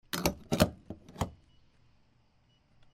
fuelstart.mp3